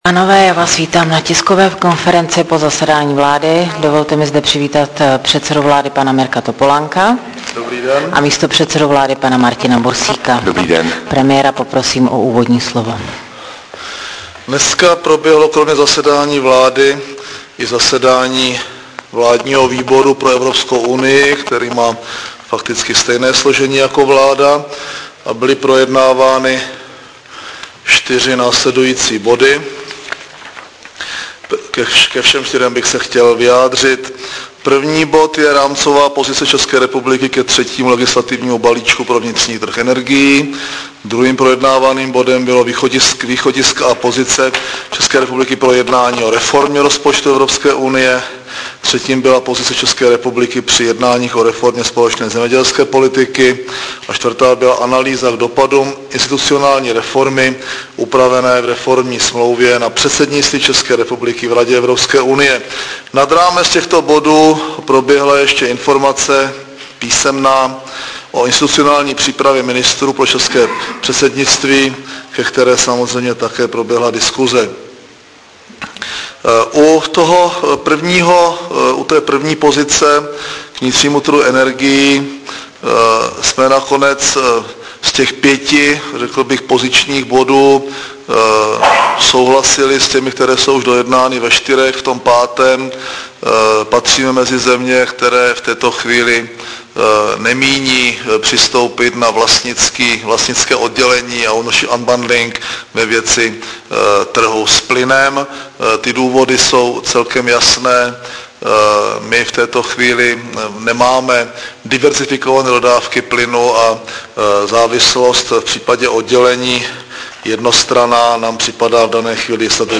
Tisková konference předsedy vlády ČR Mirka Topolánka po jednání vlády 14.11.2007